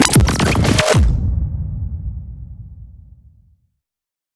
FX – 137 – BREAKER
FX-137-BREAKER.mp3